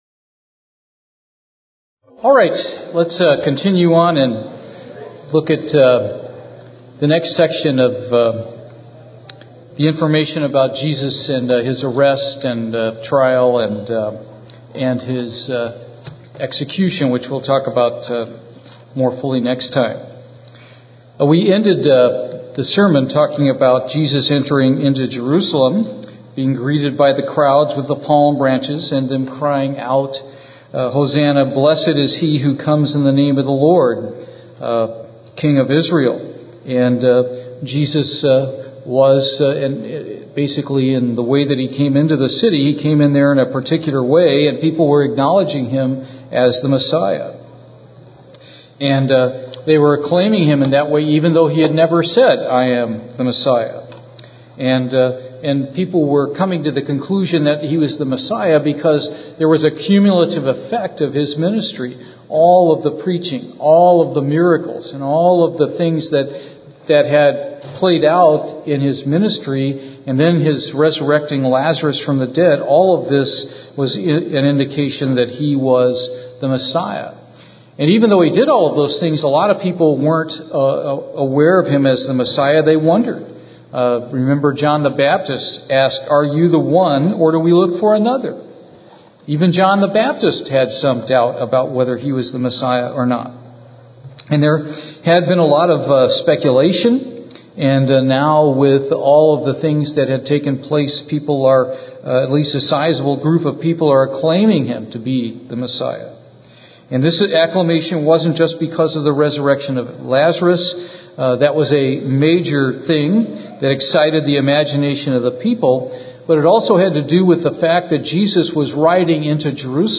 This Bible study continues the events of the arrest and trial of Jesus. It picks up with the first interrogation and moves forward to Jesus being brought before Pilate.
Given in Houston, TX